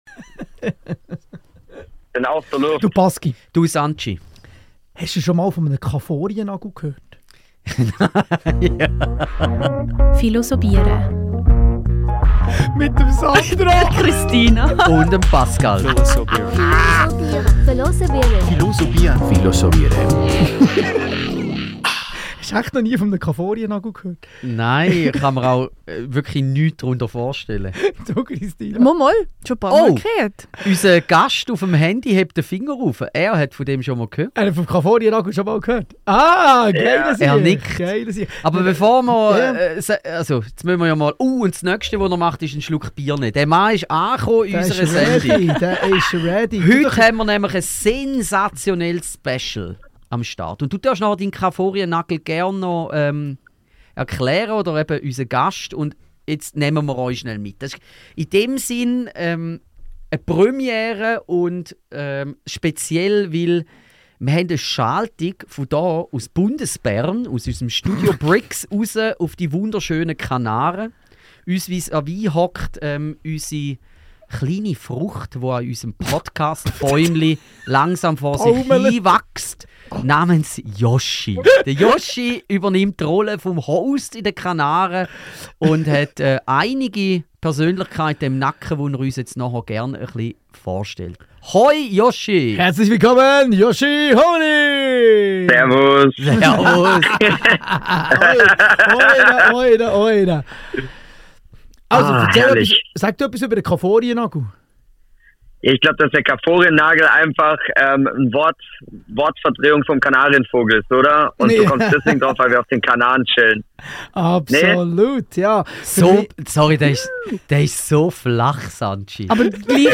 Genau, sie telefonieren mit uns live in den Podcast.